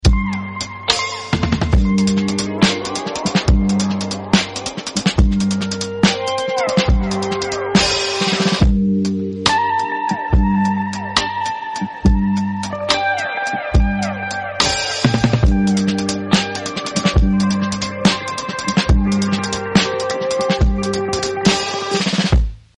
Switching From 4/4 To 3/4.